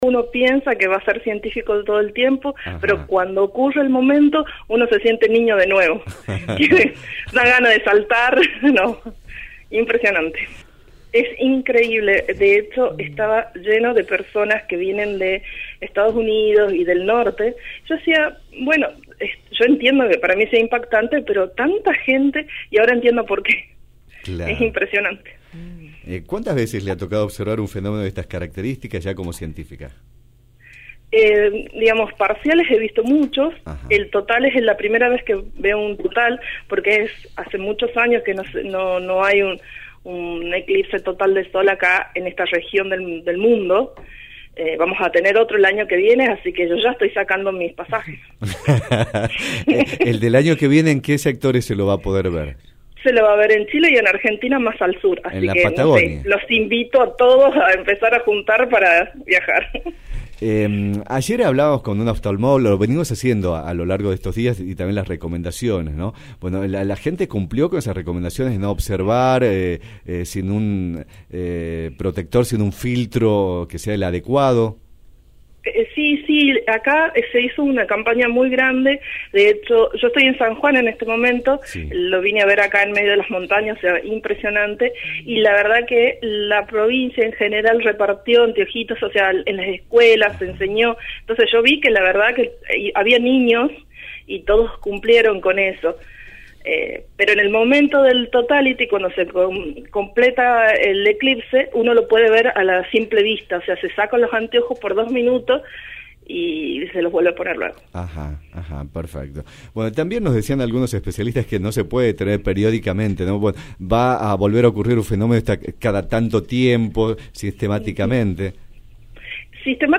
Audio-entrevista-LV12.mp3